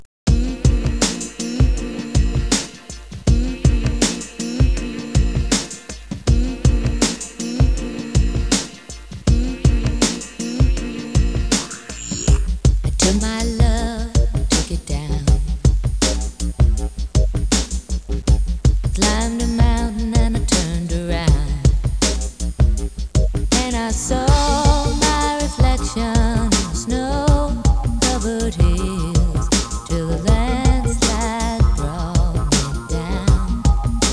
• Analog remix
analog remix (Wav, 750K)